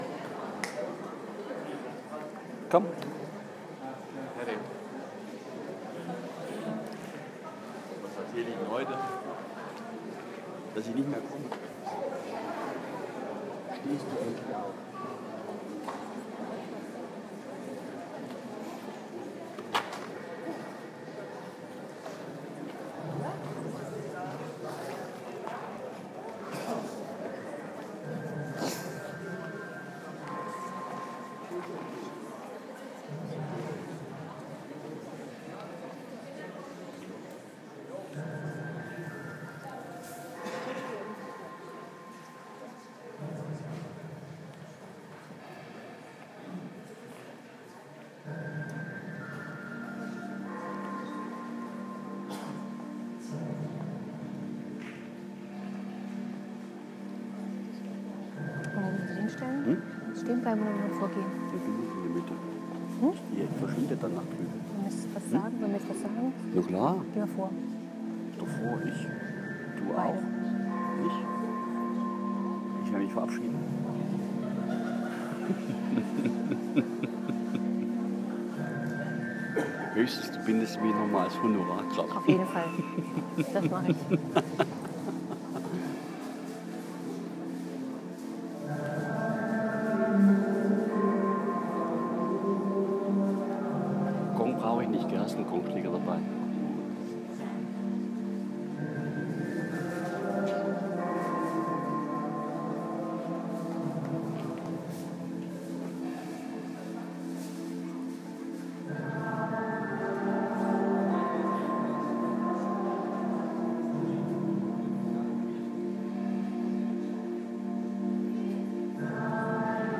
Aufnahme eines Klangkonzertes
in der Marienglashöhle